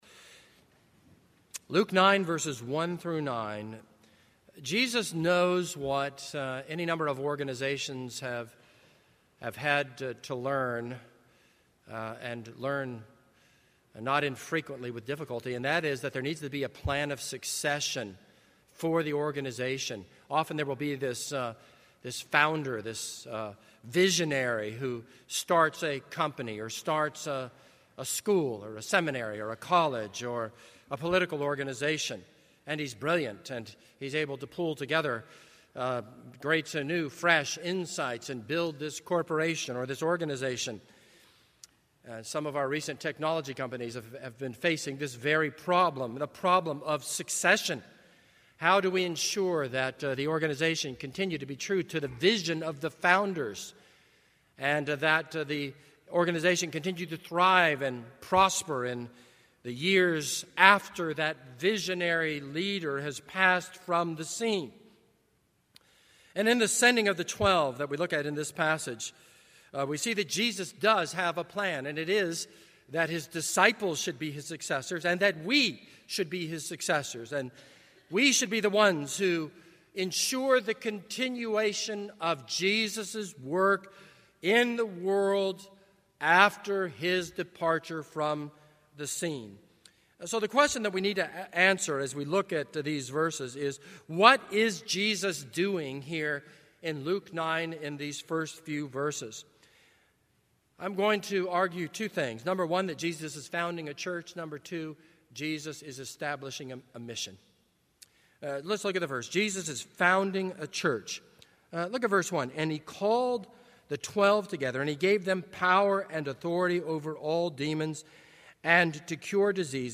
This is a sermon on Luke 9:1-9.